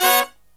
HIGH HIT01-R.wav